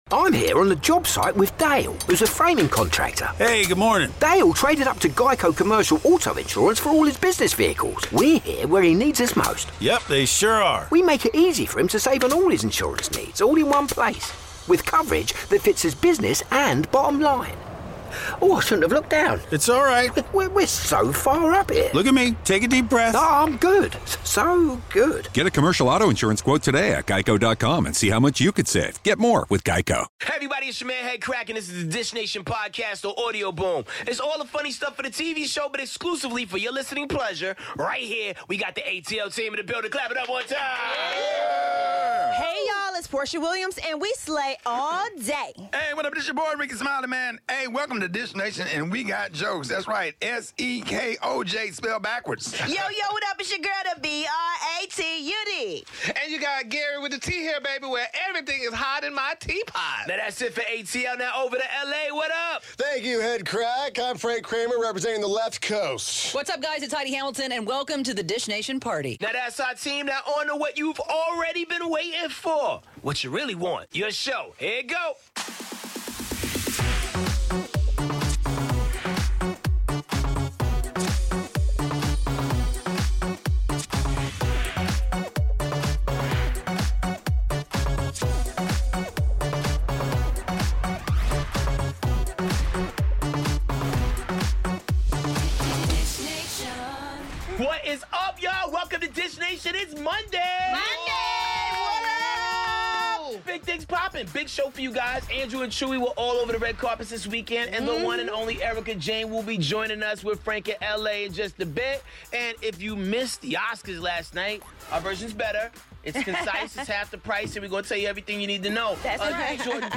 Special guest co-host: 'Real Housewives of Beverly Hills' star Erika Jayne. The best and worst moments from the 2018 Academy Awards plus all the latest celebrity news.